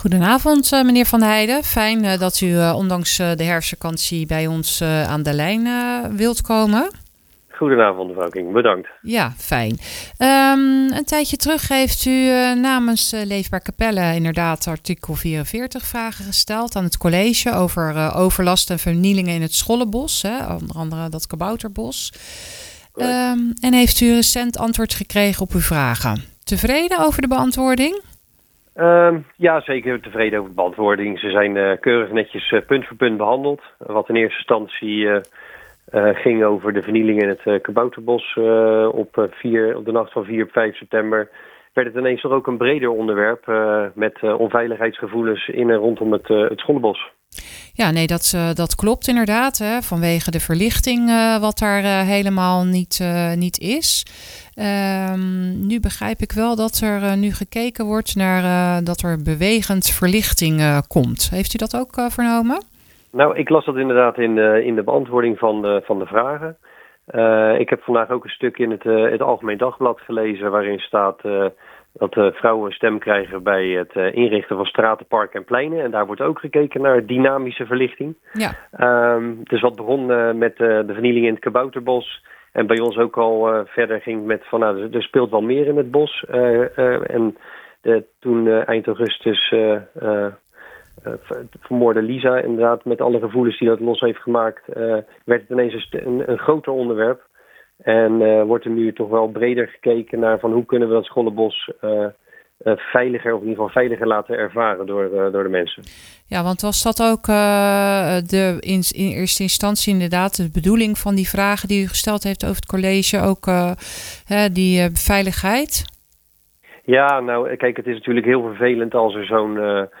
praat daarover met raadslid Jannes van der Heide die zijn reactie geeft op de antwoorden.